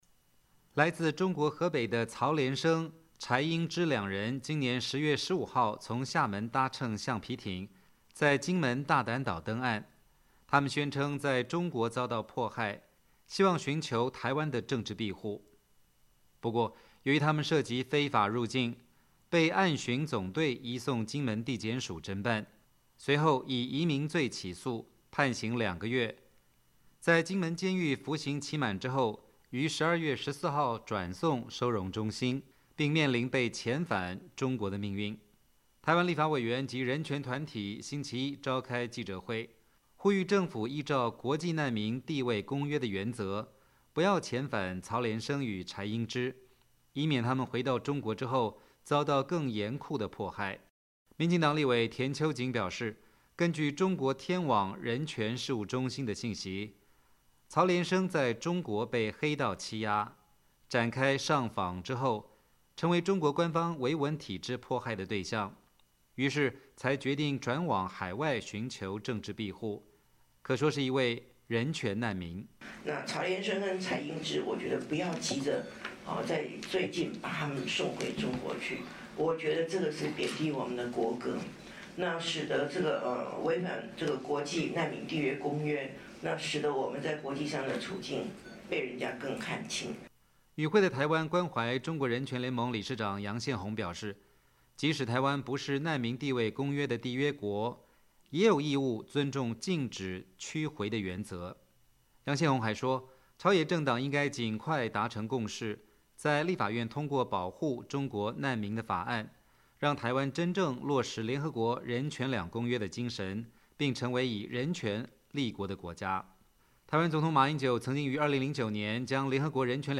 台湾民进党立委及人权团体召开记者会，呼吁政府不要遣返来台寻求政治庇护的大陆人士。